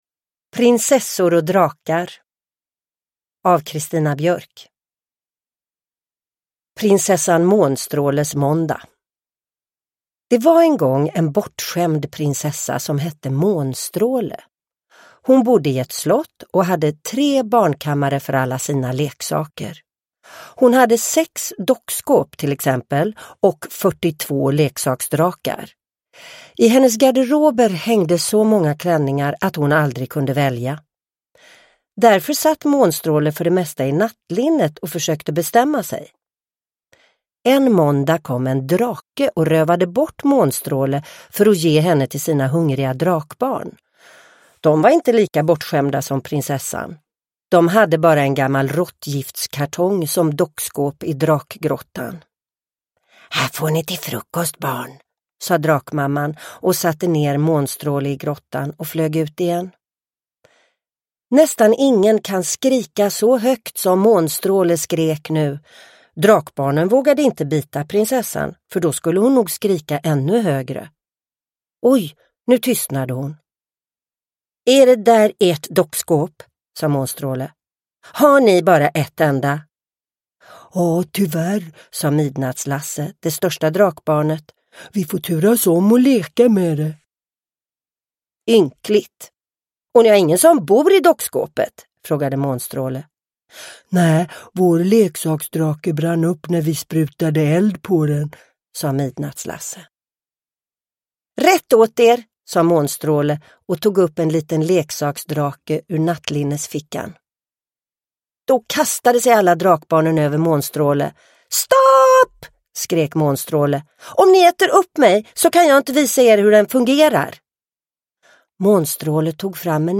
Prinsessor och drakar – Ljudbok – Laddas ner
Uppläsare: Sissela Kyle